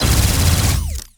Added more sound effects.
GUNAuto_Plasmid Machinegun C Burst_05_SFRMS_SCIWPNS.wav